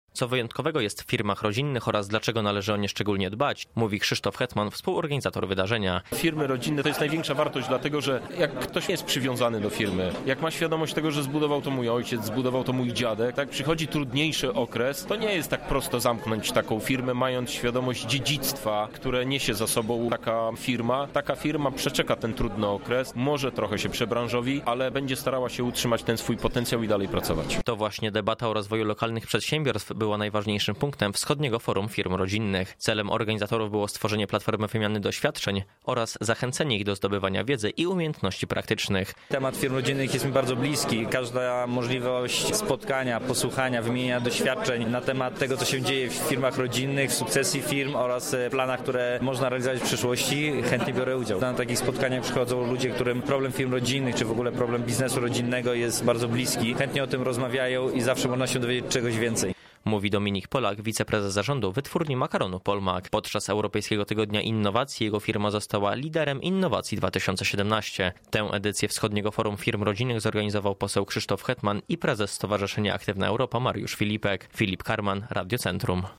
Aby omówić aktualną sytuację lokalnych przedsiębiorców w parku naukowo-technologicznym odbyło się Wschodnie Forum Firm Rodzinnych.
Na miejscu był nasz reporter